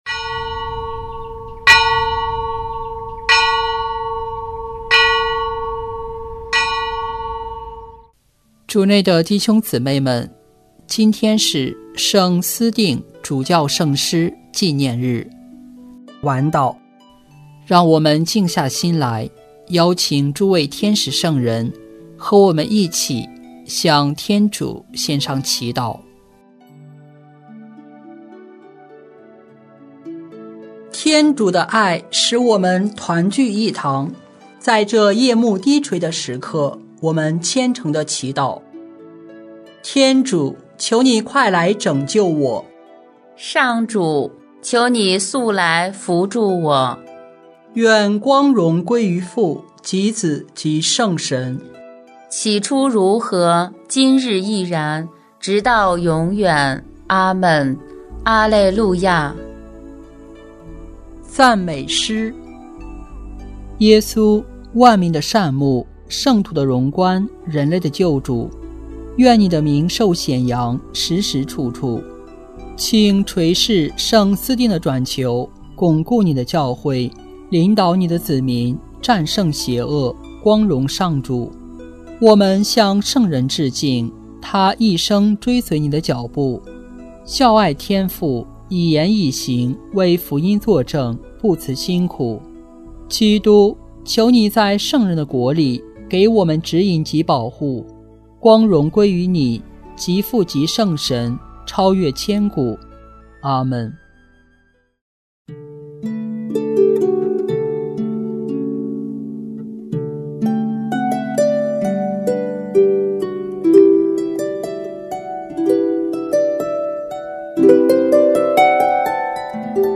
圣咏吟唱 圣咏 29 死里逃生，感谢主恩 “基督在光荣复活后感谢圣父。”